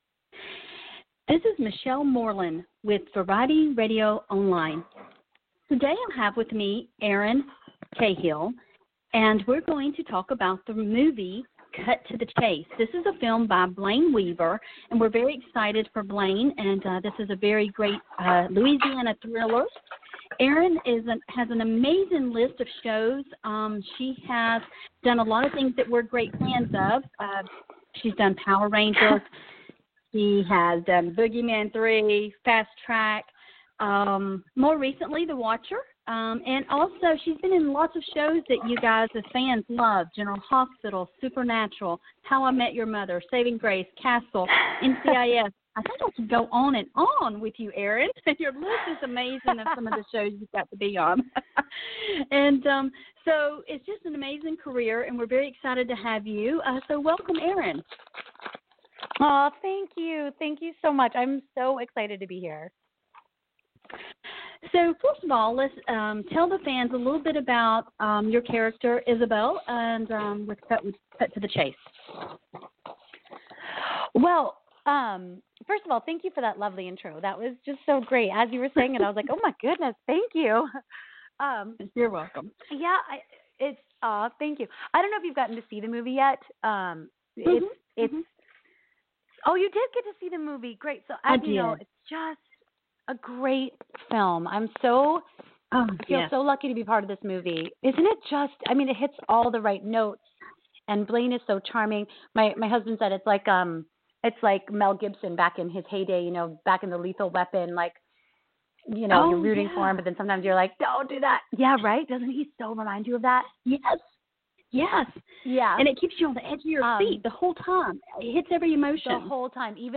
Erin Cahill 'Cut to the Chase' Interview